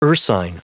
Prononciation du mot ursine en anglais (fichier audio)
Prononciation du mot : ursine